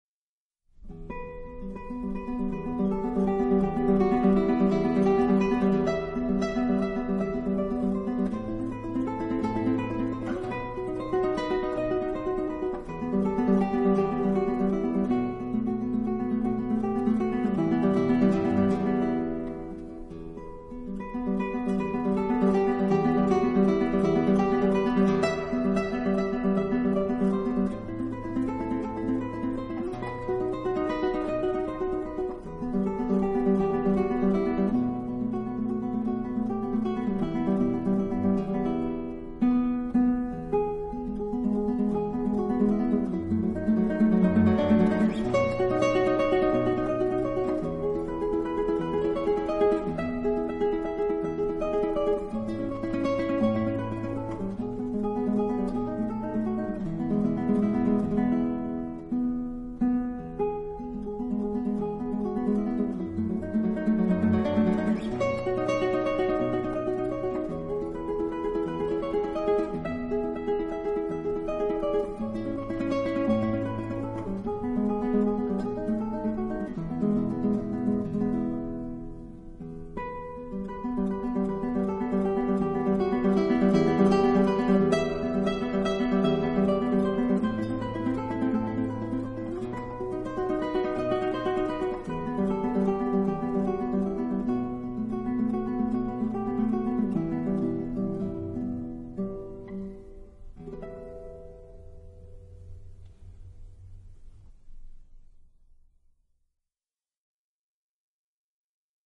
专辑歌手：纯音乐